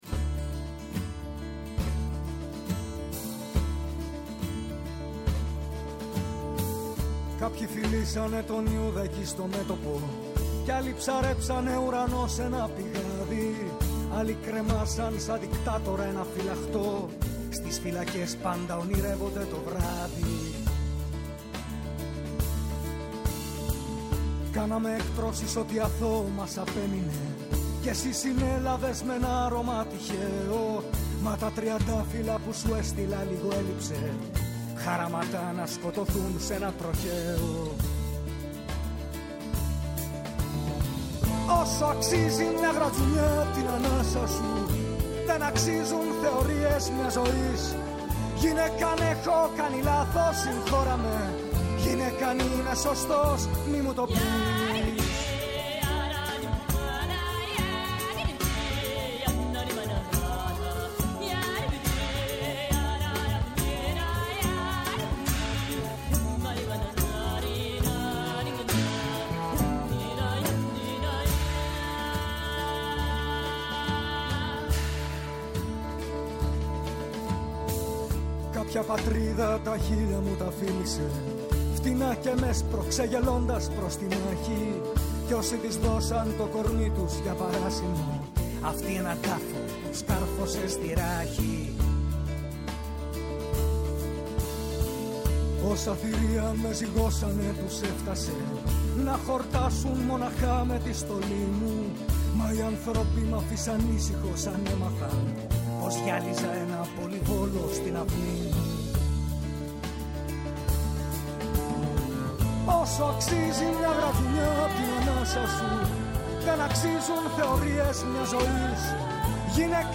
Απόψε συζητάμε με τη συγγραφέα και επικοινωνιολόγο